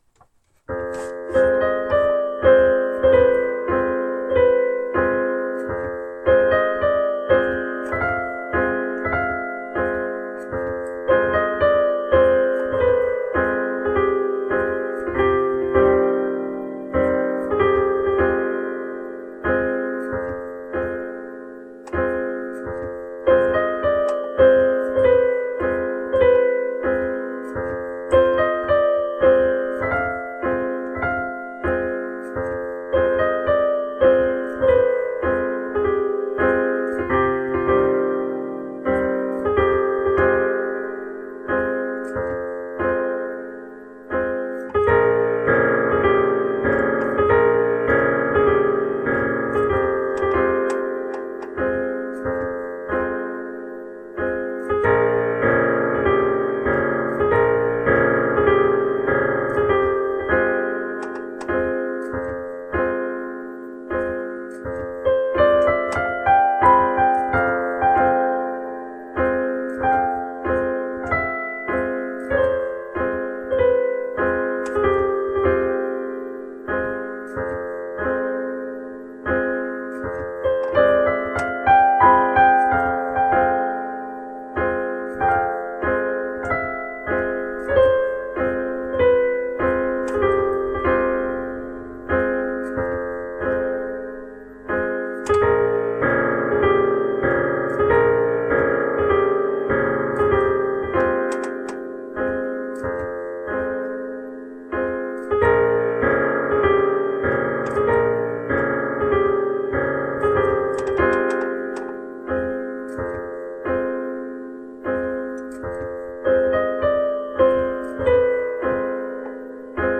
ピアノ
グノシエンヌ第1番 アマチュア初中級者+エントリークラスの電ピ+スマホで直録りなので聴くに堪えないのは分かってる、分かってるんだ 「もっとこうした方がいいぞド素人」的なご助言なども頂けると大変助かります つかうちの環境だとWebUIのプレーヤーが動かんな。